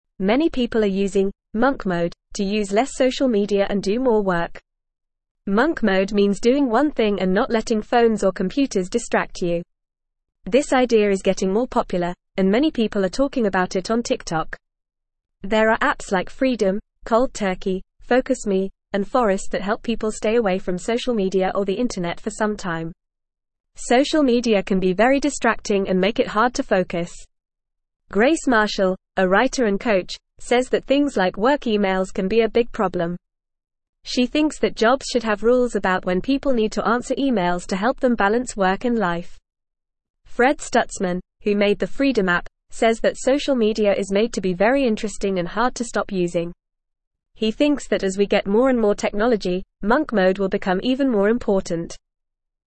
Fast
English-Newsroom-Beginner-FAST-Reading-Monk-Mode-Using-Less-Social-Media-Doing-More-Work.mp3